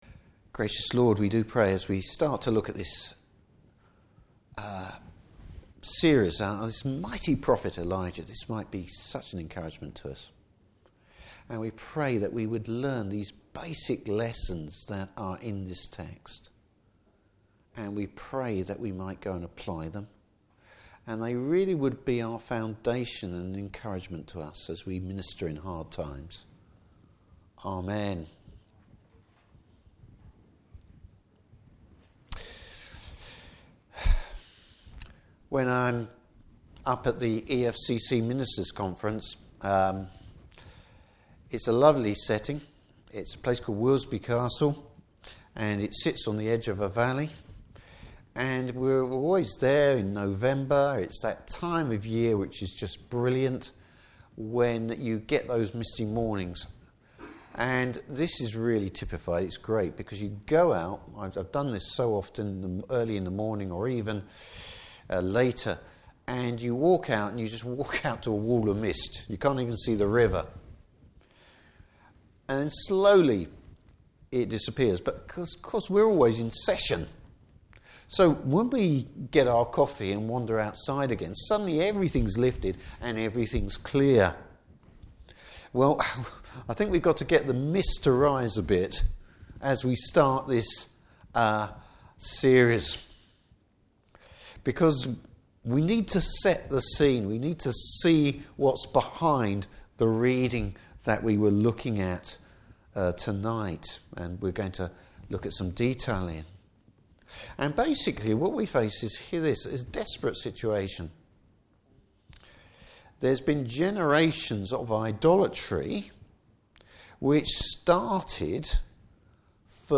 Service Type: Evening Service Even in the worst of times, God is still at work!